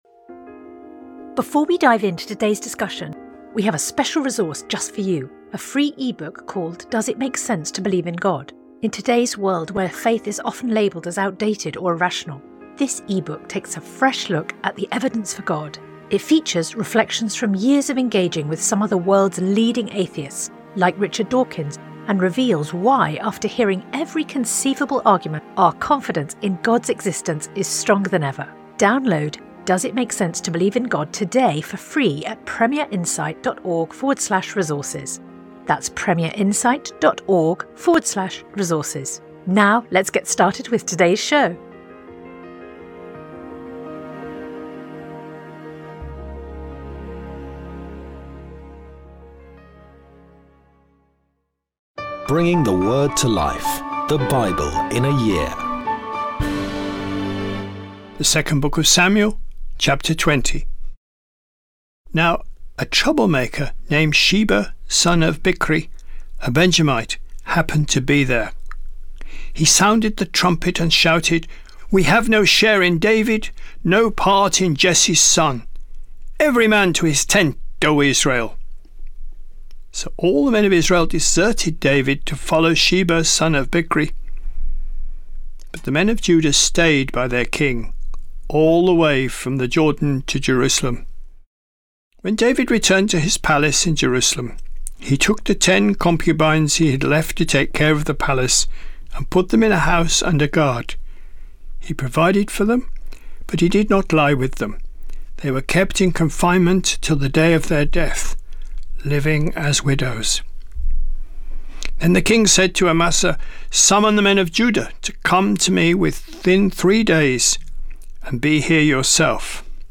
Today’s reading comes from 2 Samuel 20-21; 1 Corinthians 1 Sponsored ad Sponsored ad